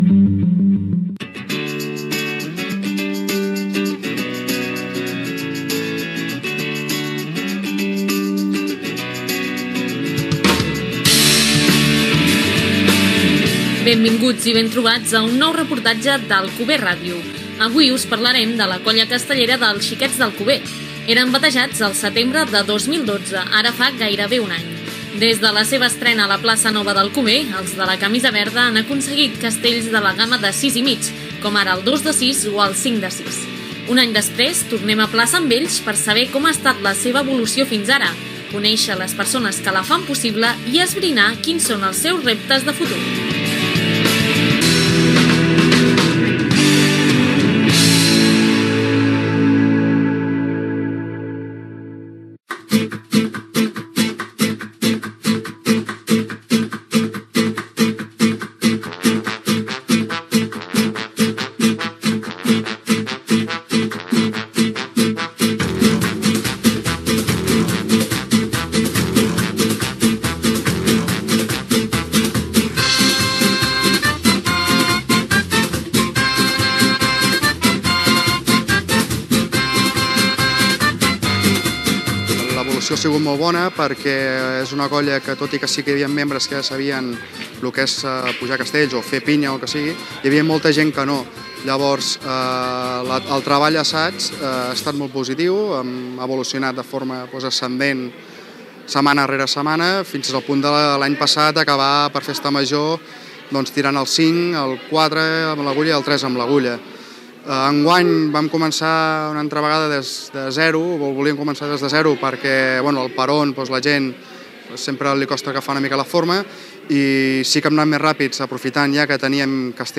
Identificació de l'emissora i reportatge sobre la colla castellera dels Xiquets d'Alcover, poc abans de complir el seu primer aniversari
Informatiu